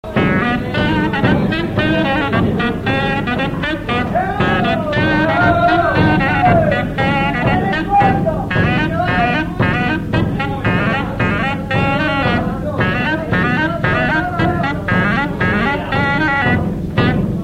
Chants brefs - A danser
danse : gigouillette
Pièce musicale inédite